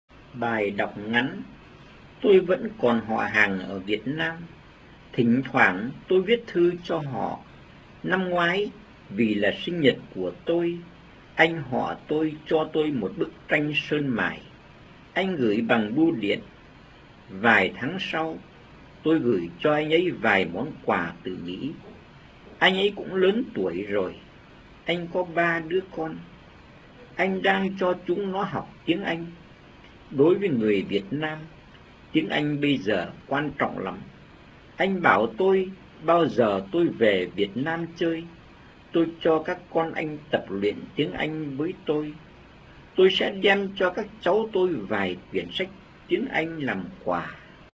Baøi ñoïc ngaêÙn